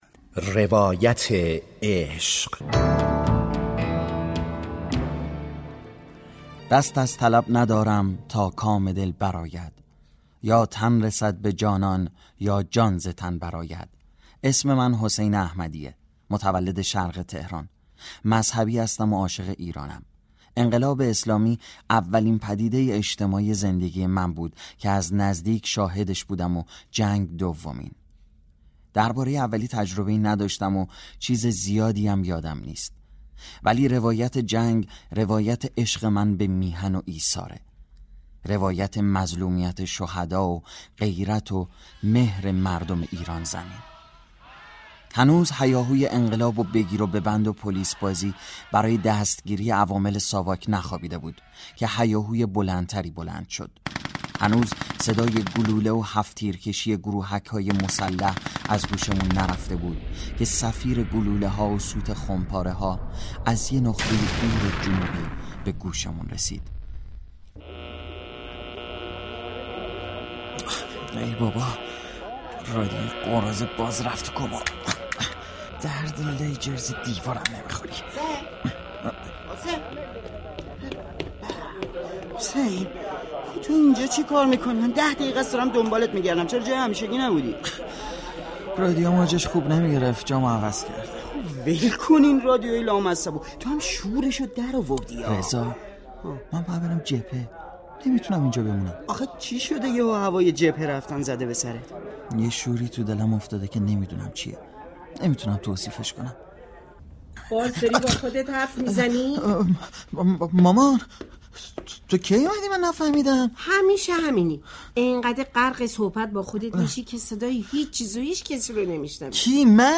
از اول آذرماه ، نمایش رادیویی